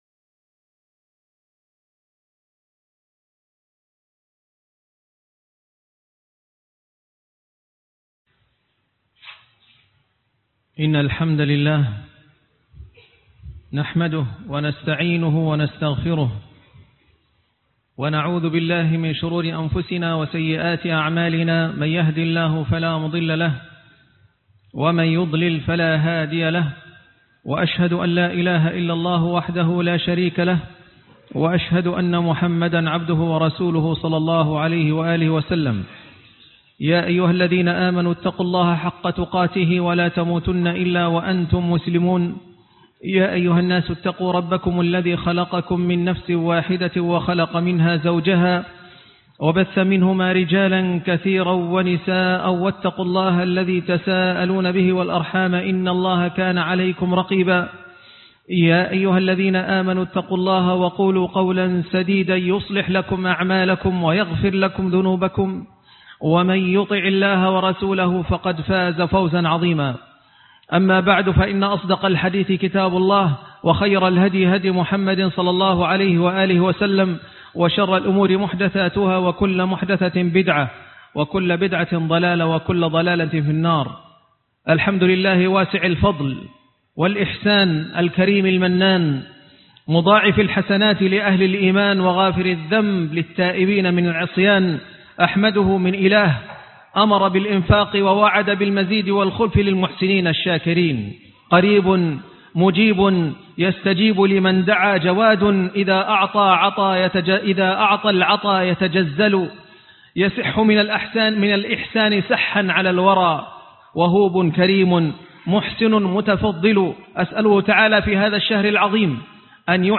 فضل الصدقة - خطبة الجمعة